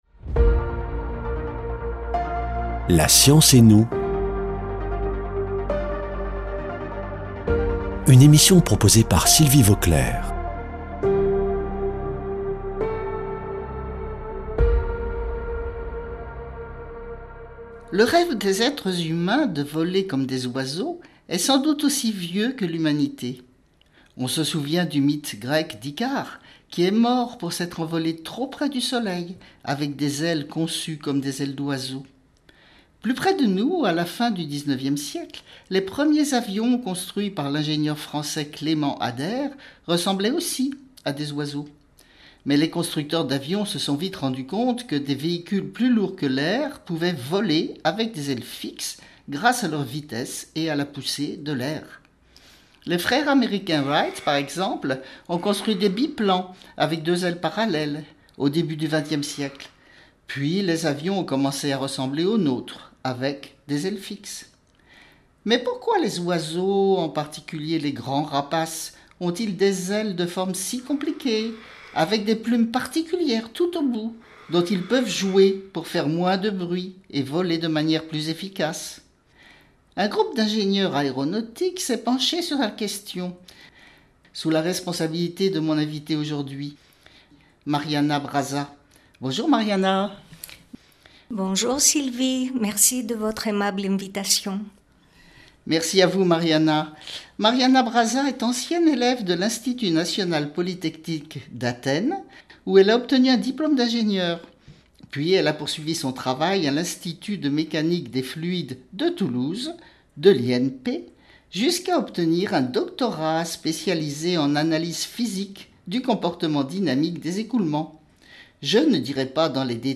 Une émission